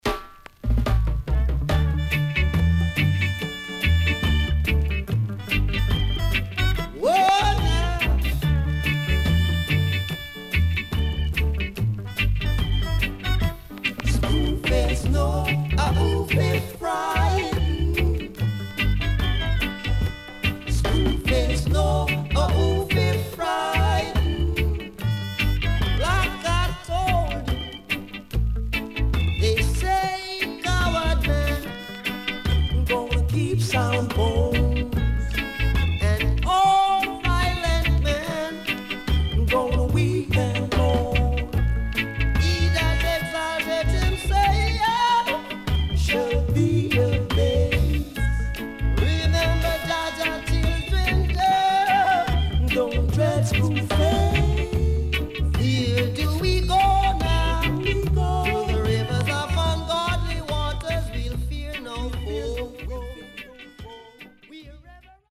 Deep & Killer Roots Vocal.Different Take.Good Condition
SIDE A:少しチリノイズ入りますが良好です。